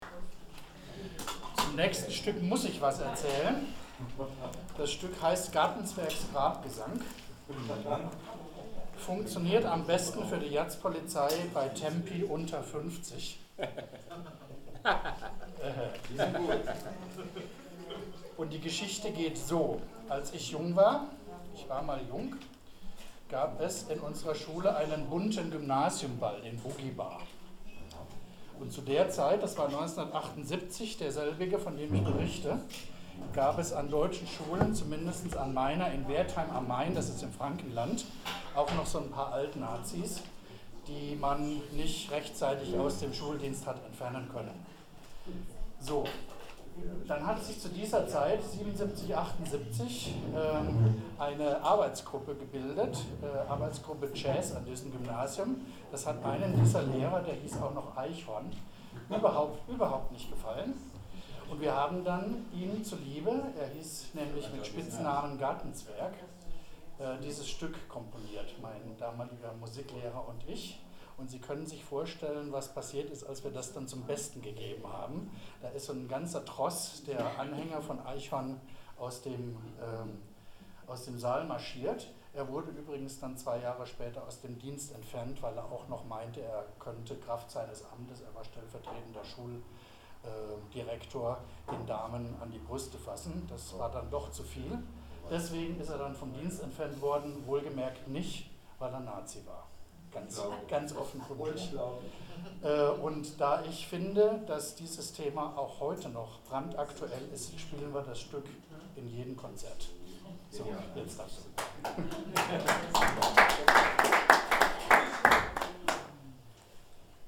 16 16 Ansage [01:57]